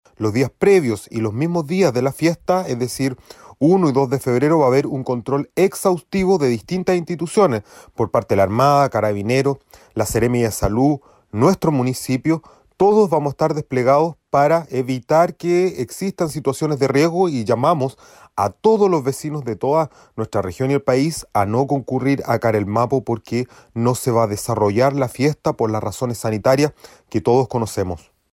Los detalles los entregó el alcalde de Maullín, Jorge Westermaier, acerca de lo que cada año se recuerda los días 01 y 02 de febrero.